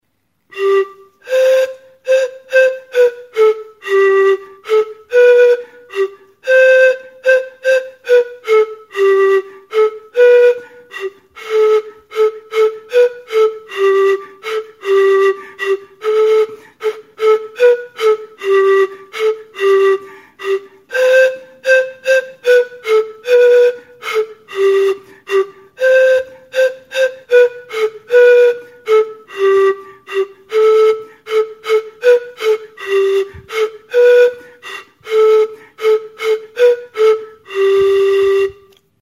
Aerophones -> Flutes -> Pan flute
Recorded with this music instrument.
FLAUTA; Pan flauta
Pan flauta, kanaberazko 5 tutuekin egina (bataz beste 1,3 cm-ko diametrokoak).